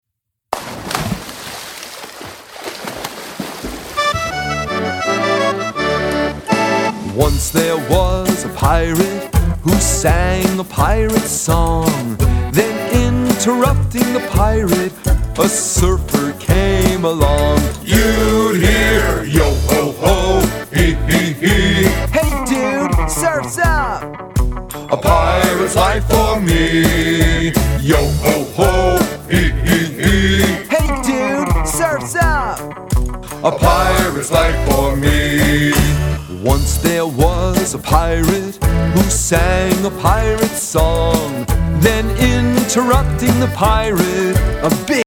Hip-Hop and Pop Beats to Help Your Children Read